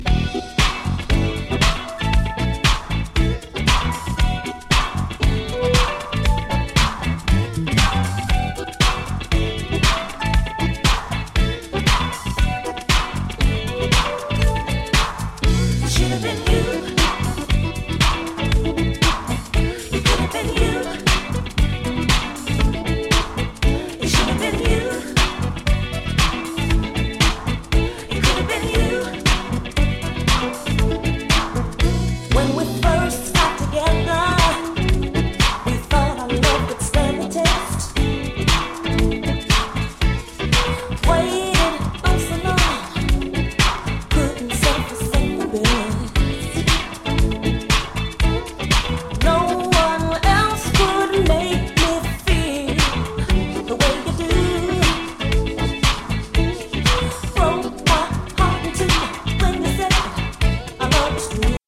SOUL & FUNK & JAZZ & etc / REGGAE & DUB